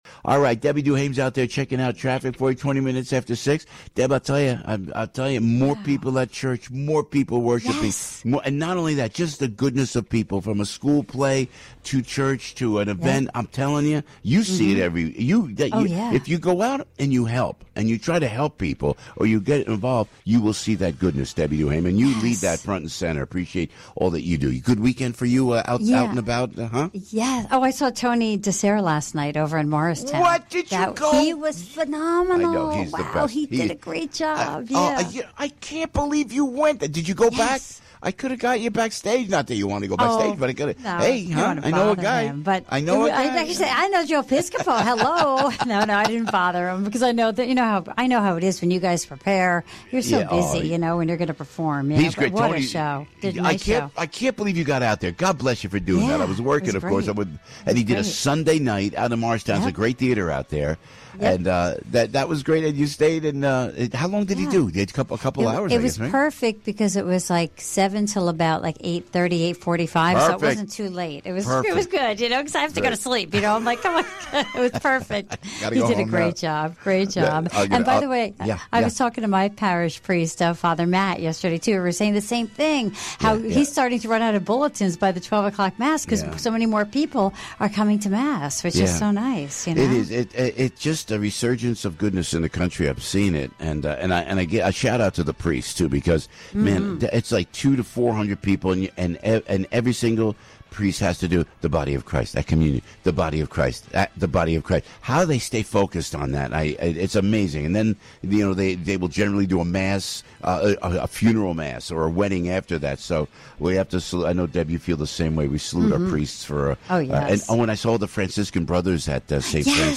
Here are some sound bites from AM970 The Answer
Joe Piscopo Show Traffic Report AM970 The Answer